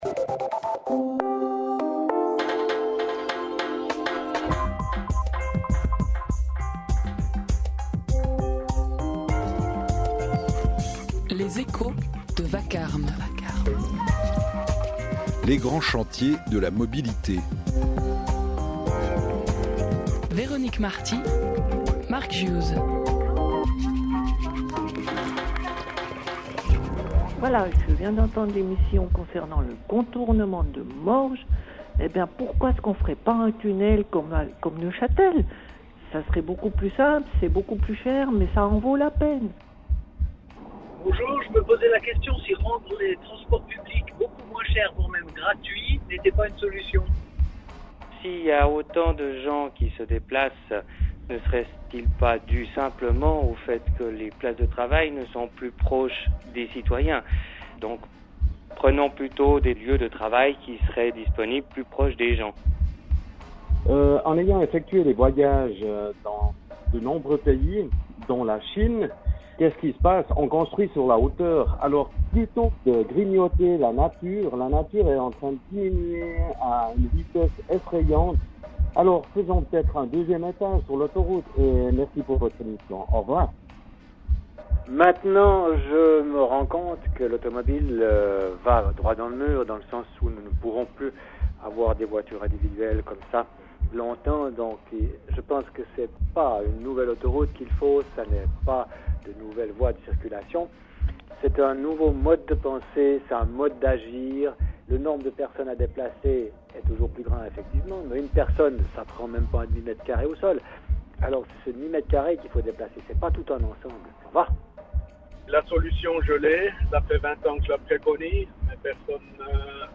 Une émission de la 1ère, RTS: Vacarme , le 30 août 2015, de 9h00 à 10h00.